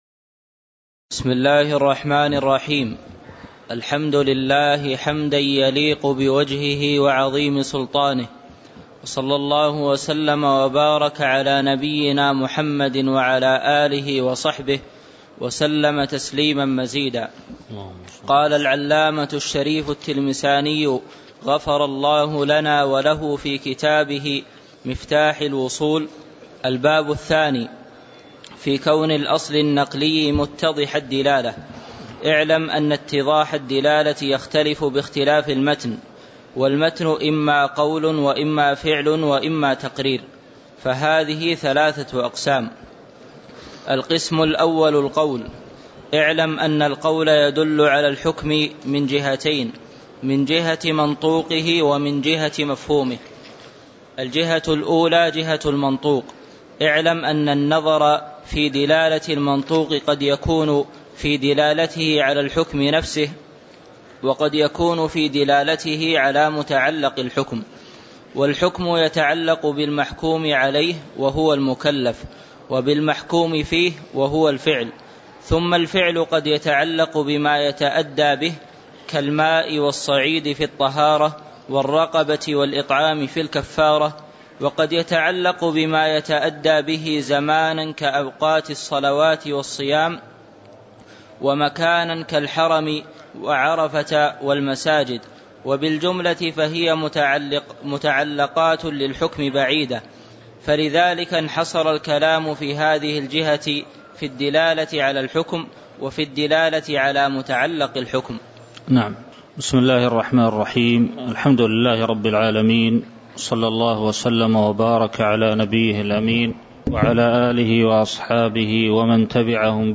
تاريخ النشر ١٢ جمادى الأولى ١٤٤١ هـ المكان: المسجد النبوي الشيخ